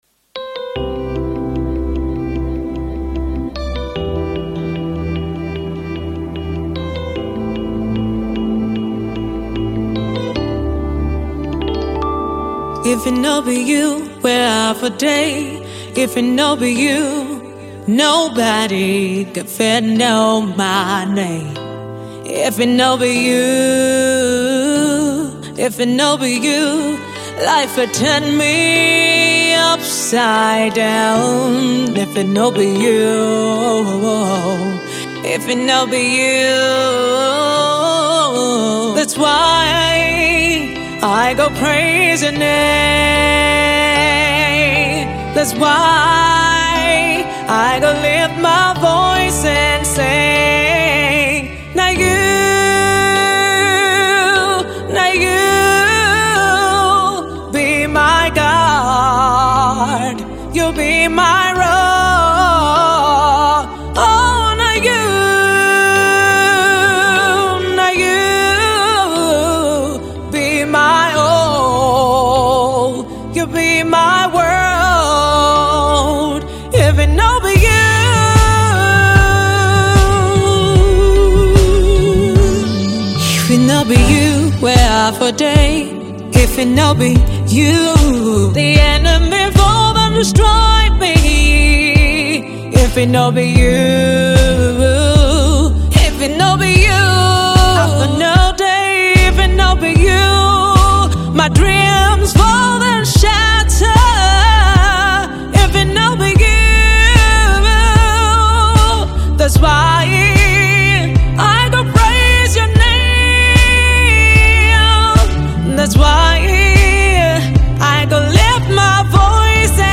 smooth vocals
a blend of soul, RNB and afro pop infused in her sound.
worship song